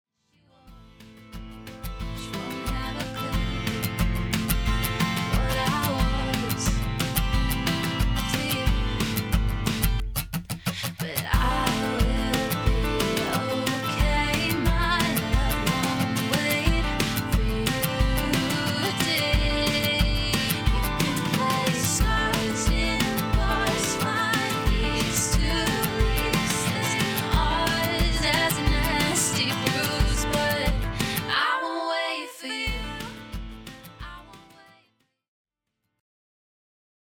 Country/Folk/Acoustic